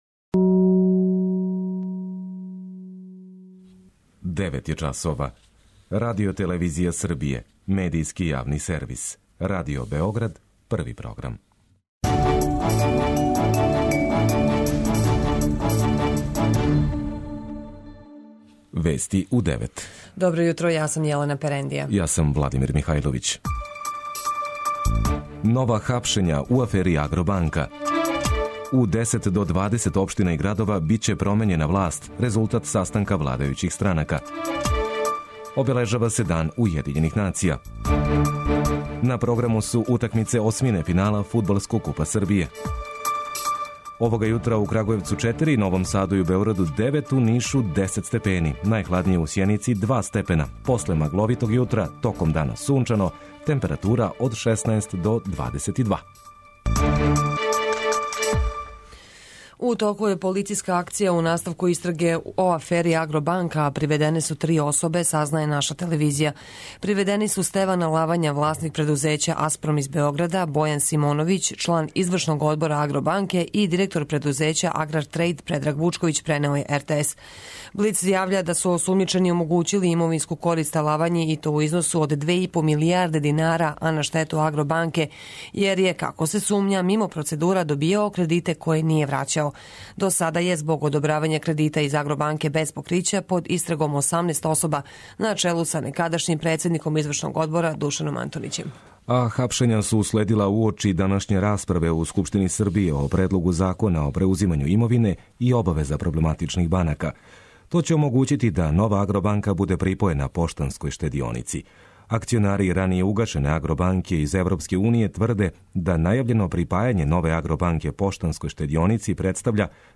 Уредници и водитељи
преузми : 9.64 MB Вести у 9 Autor: разни аутори Преглед најважнијиx информација из земље из света.